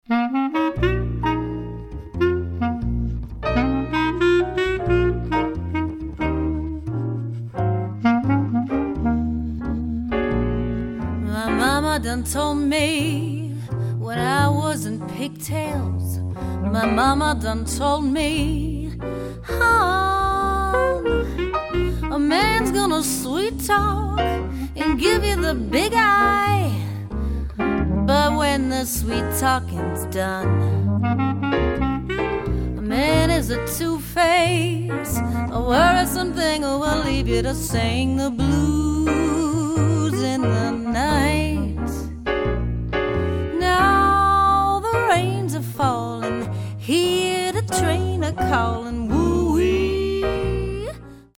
A night of jazz to remember.